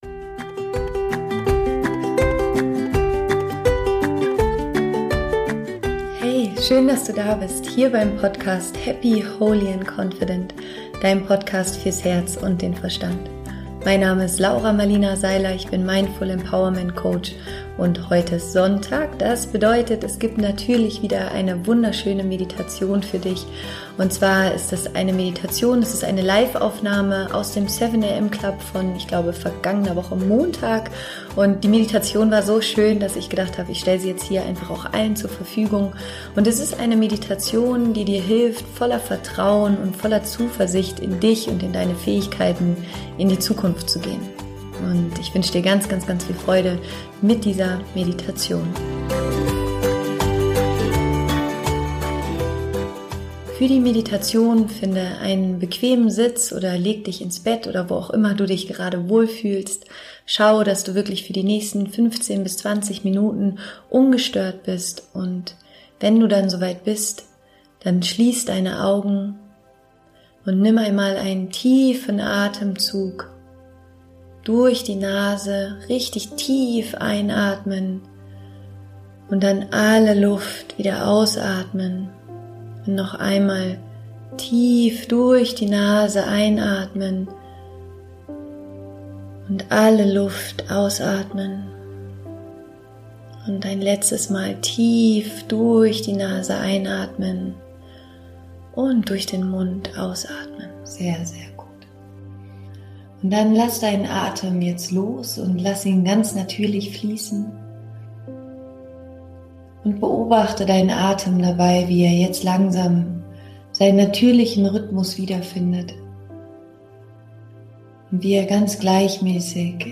Heute wartet wieder eine neue geleitete Meditation auf dich. Die Meditation hilft dir dabei voller Vertrauen und Selbstbewusstsein in deine Zukunft zu sehen.